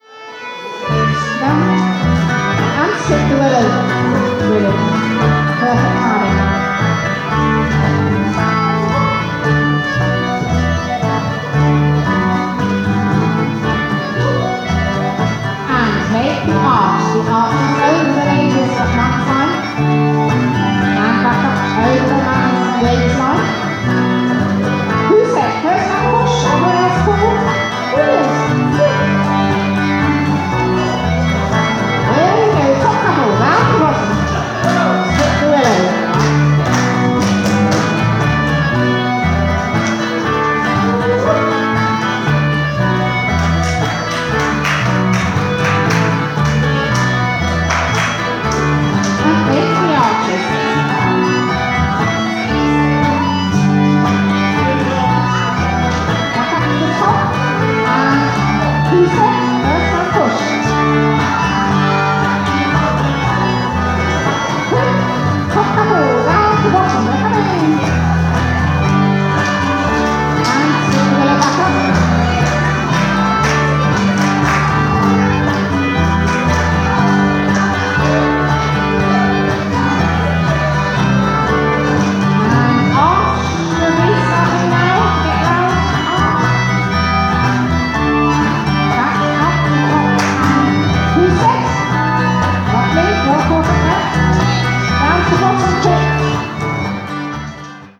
Our style is on the rocky/jazzy side of folk, though we are always sensitive to the audience's preferences on volume level.
MacPherson’s Lament (Reel).mp3
MacPhersons Lament Scottish Reel.mp3